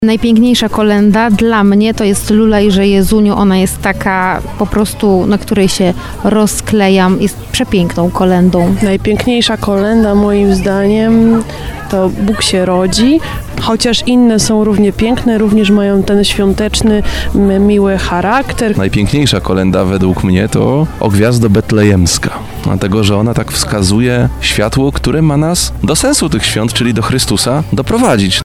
Zapytaliśmy mieszkańców regionu, jakich kolęd nie może zabraknąć przy świątecznym stole w ich domach:
25koleda_sonda.mp3